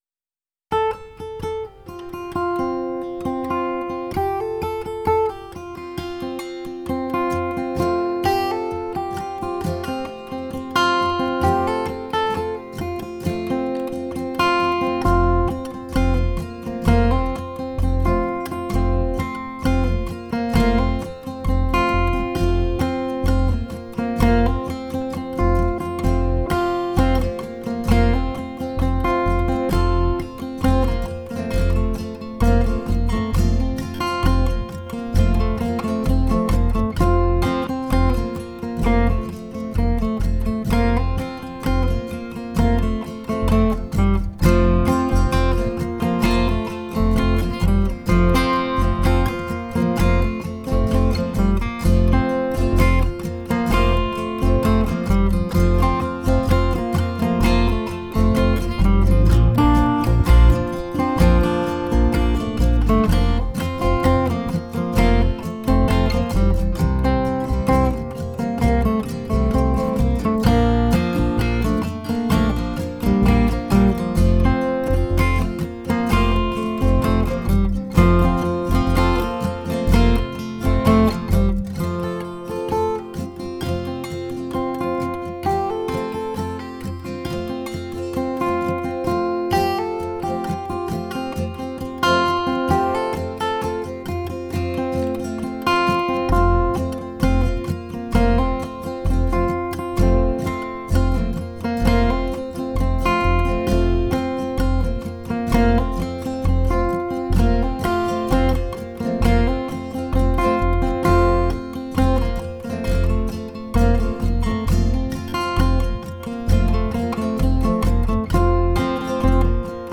Instrumentals for Guitar
It’s just in a good mood.